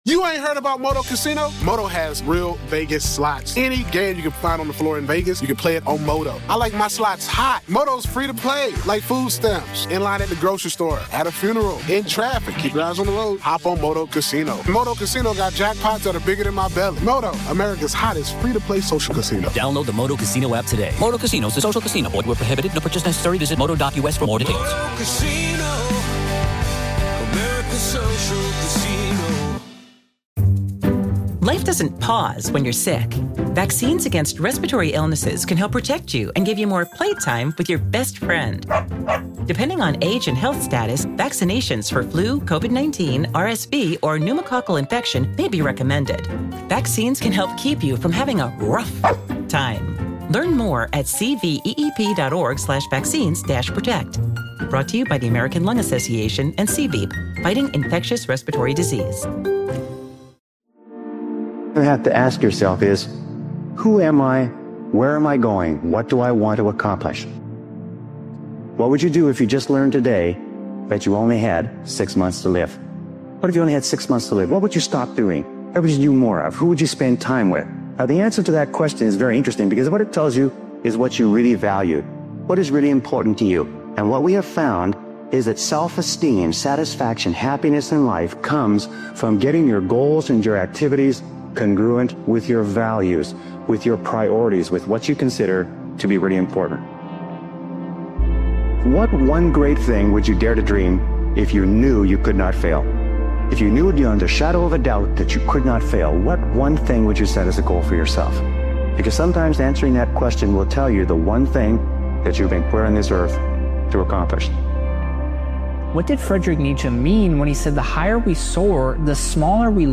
This is the motivational speeches compilation dives into the truth: becoming HIM isn’t about talent or hype, it’s about consistency, discipline, and mindset.